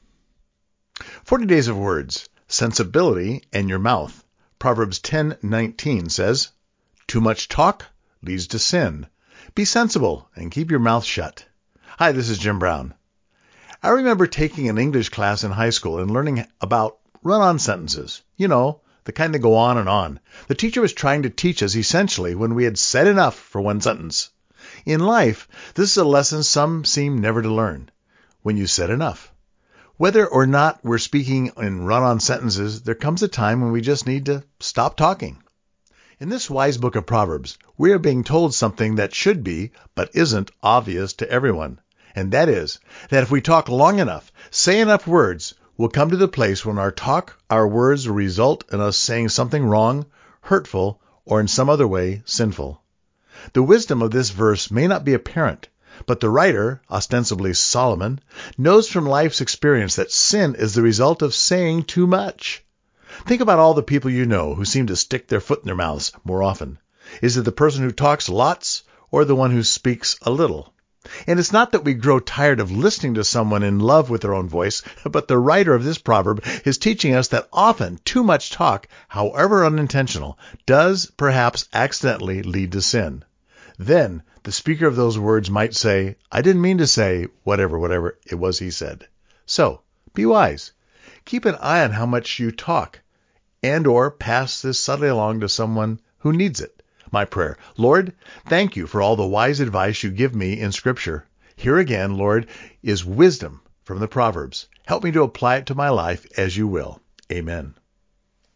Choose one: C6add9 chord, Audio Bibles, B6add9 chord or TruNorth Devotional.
TruNorth Devotional